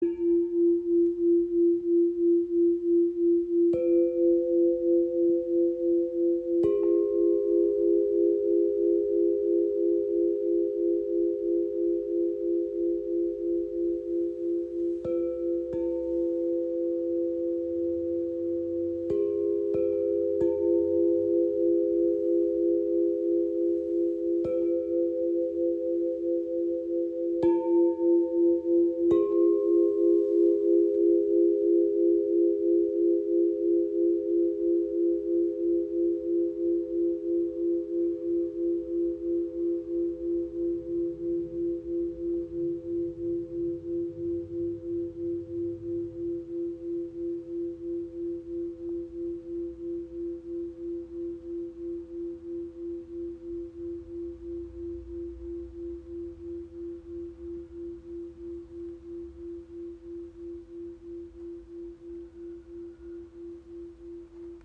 • Icon Drei harmonische Töne für weiche und zugleich kraftvolle Klangteppiche
Chordium L50 Klangröhren F Minor | F4-Ab4-C in 432 Hz
F Moll (F Ab C): sanft und melancholisch, ideal für tiefe emotionale Prozesse